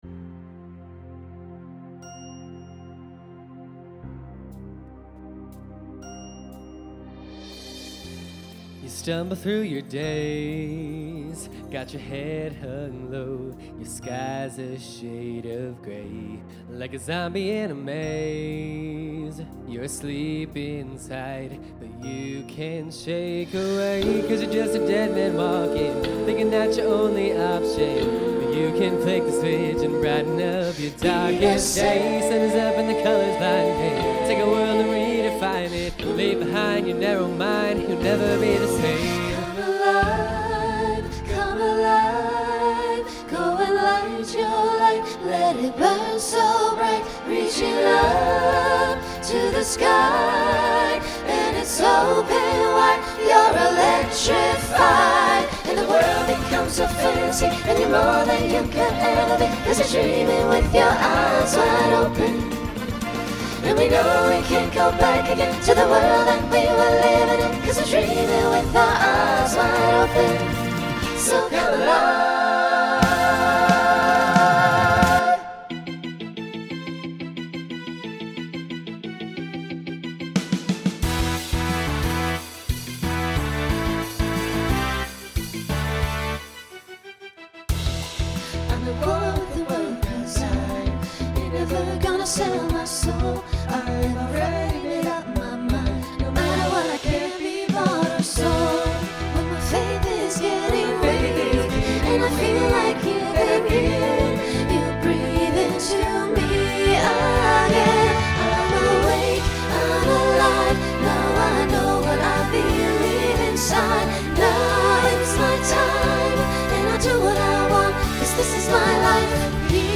Broadway/Film , Rock Instrumental combo
Voicing SATB